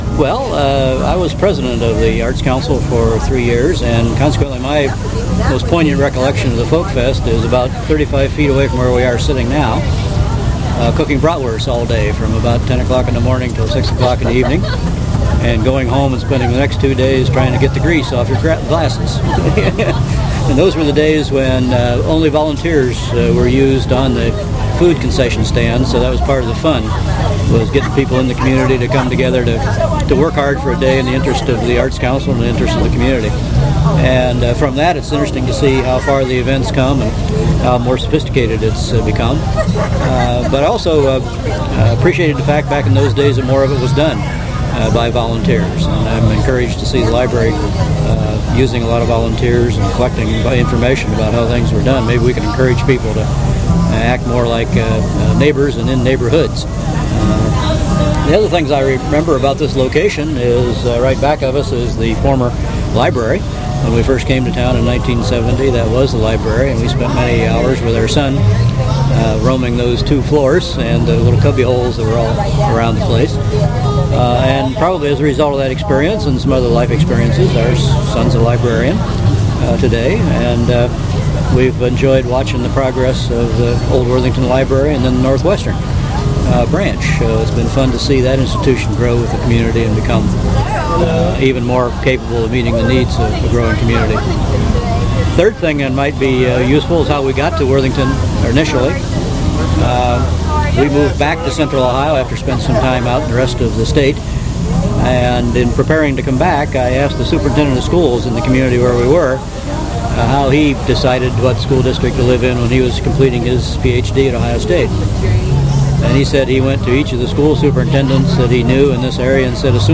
Oral History
In July 2001 Worthington Libraries invited the community to share their earliest memories and fondest recollections of life in Worthington at the Worthington FolkFEST.